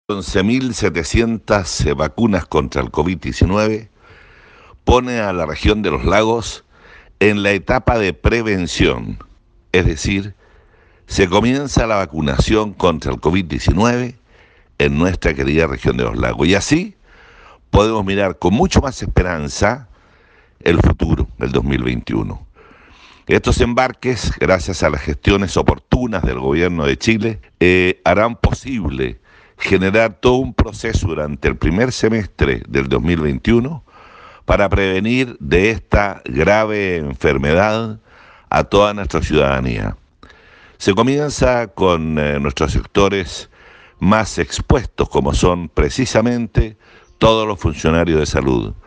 Sobre el arribo a la región de estas vacunas, se mostró muy satisfecho el intendente de Los Lagos, Harry Jurgensen.
31-VACUNAS-INTENDENTE.mp3